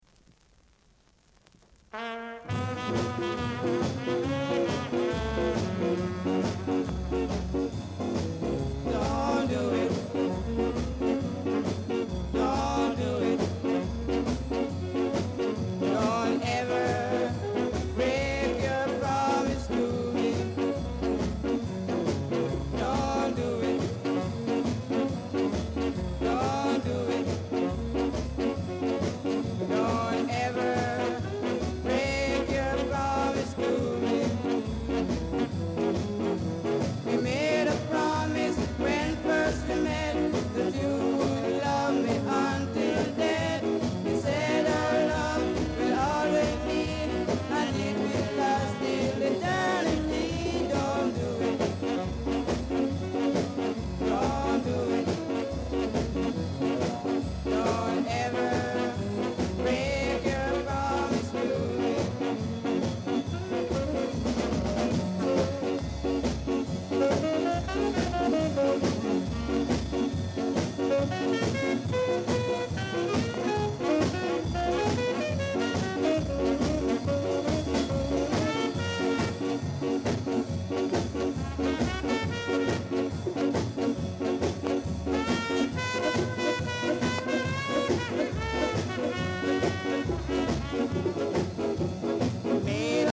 Male Vocal
Very rare! great ska jazz inst & nice vocal!